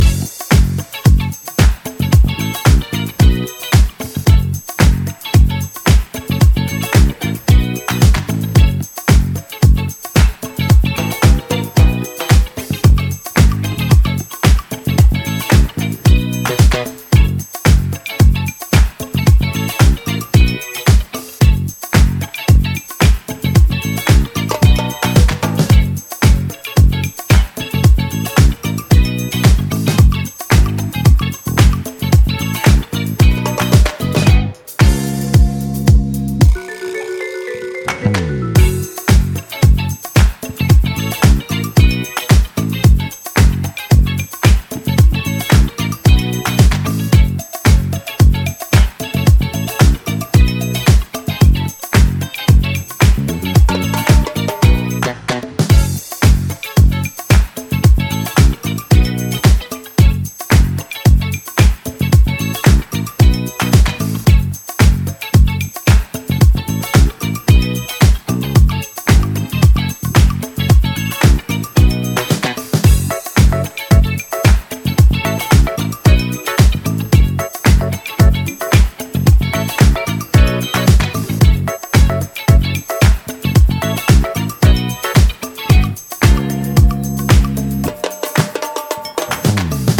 大推薦のフィメール・ヴォーカル・ディスコハウス！！
ジャンル(スタイル) DISCO / NU DISCO